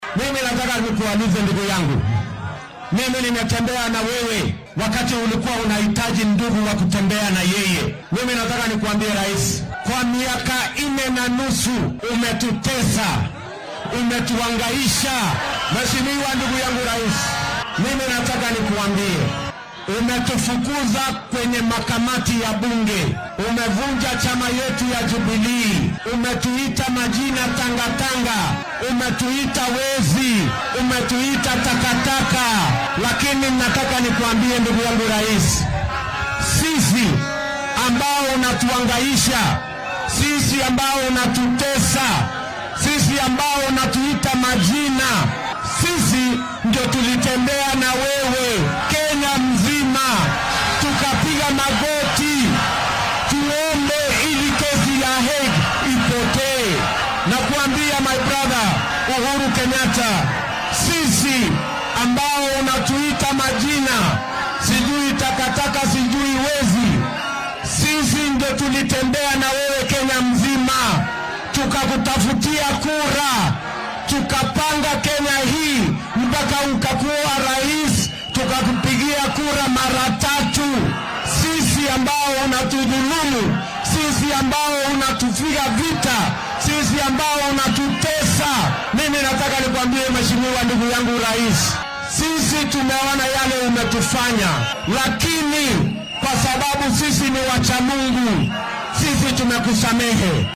William Ruto oo shalay isku soo bax siyaasadeed ku qabtay fagaaraha Thika Stadium ee ismaamulka Kiambu ayaa sheegay in afartii sano ee ugu dambeysay la dhibaateynayay isaga iyo kuwa ka ag dhow.